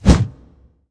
swing3.wav